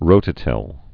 (rōtə-tĭl)